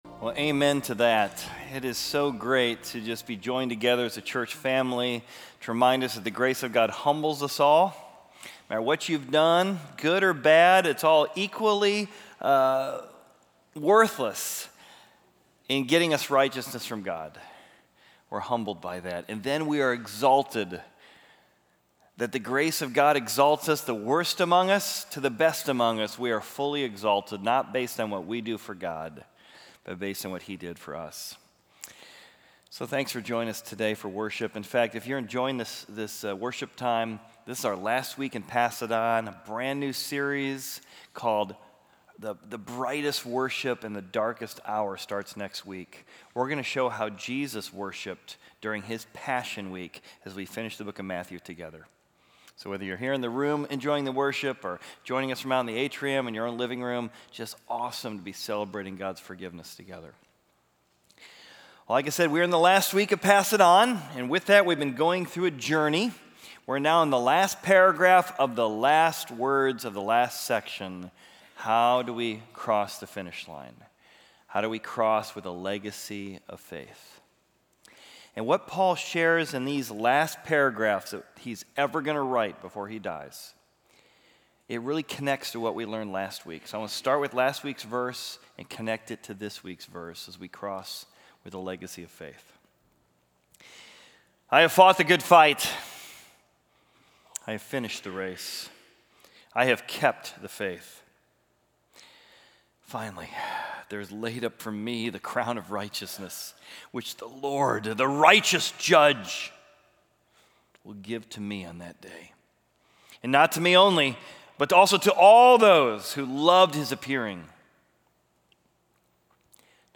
Equipping Service / 2 Timothy: Pass It On / Let the Lord Strengthen You